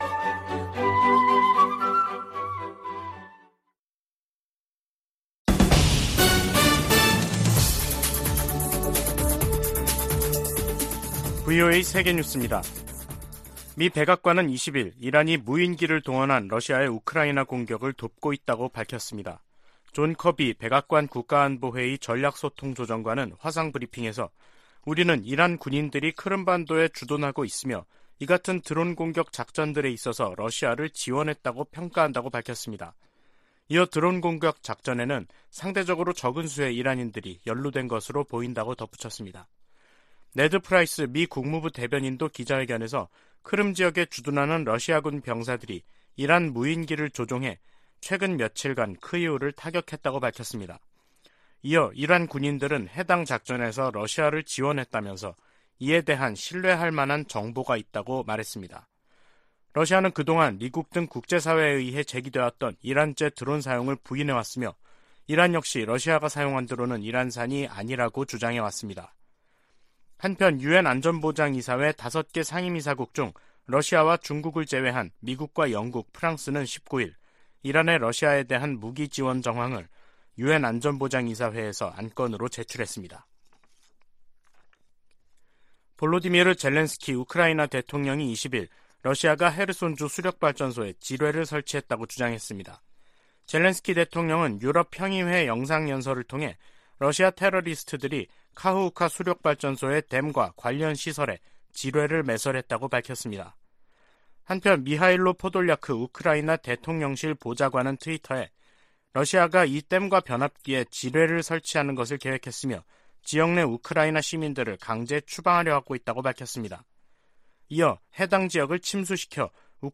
VOA 한국어 간판 뉴스 프로그램 '뉴스 투데이', 2022년 10월 21일 2부 방송입니다. 조 바이든 미국 대통령은 모든 방어역량을 동원해 한국에 확장억제를 제공하겠다는 약속을 확인했다고 국무부 고위당국자가 전했습니다. 미 국방부가 북한의 잠재적인 추가 핵실험 준비 움직임을 주시하고 있다고 밝혔습니다. 미국 일각에서는 북한의 핵 보유를 인정하고 대화로 문제를 풀어야 한다는 주장이 나오지만 대다수 전문가들은 정당성을 부여해서는 안된다는 입장입니다.